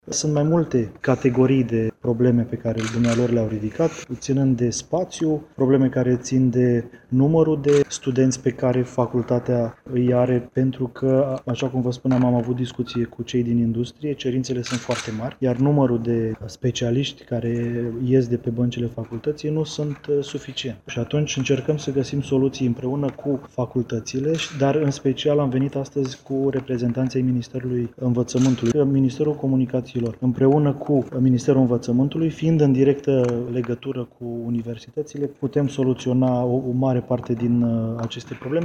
Ministrul Comunicaţiilor, Bogdan Cojocaru: